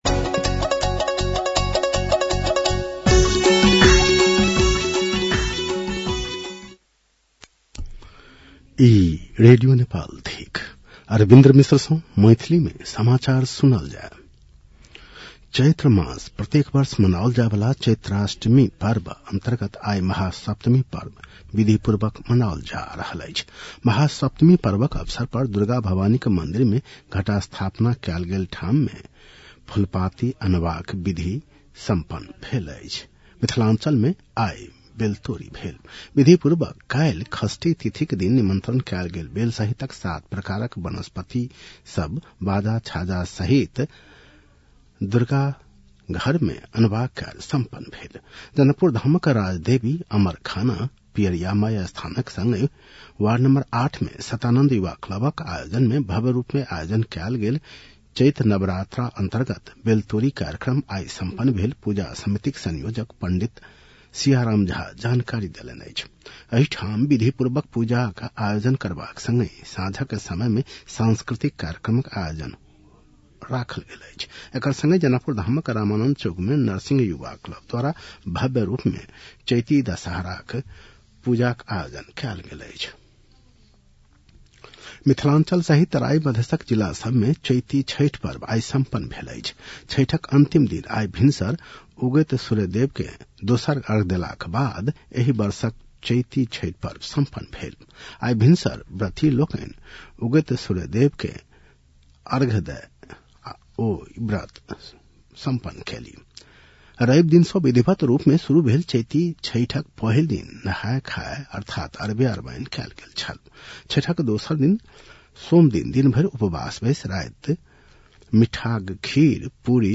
मैथिली भाषामा समाचार : ११ चैत , २०८२